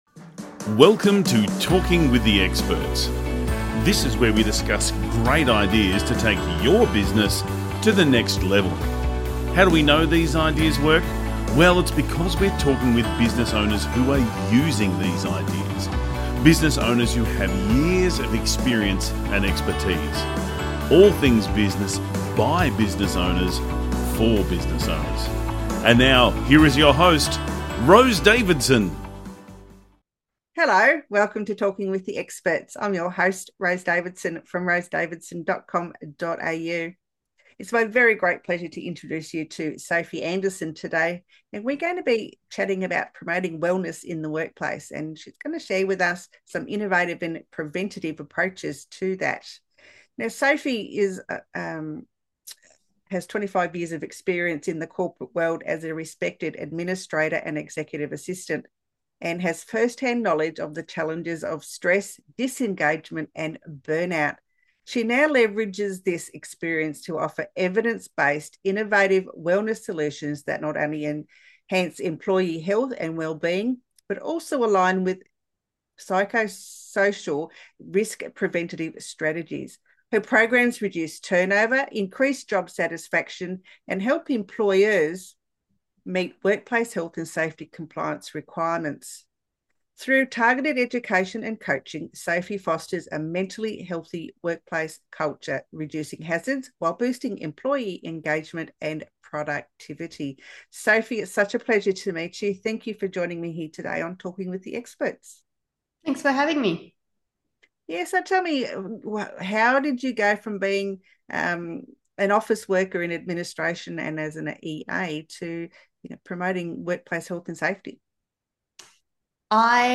💡 Three Key Points from the Interview: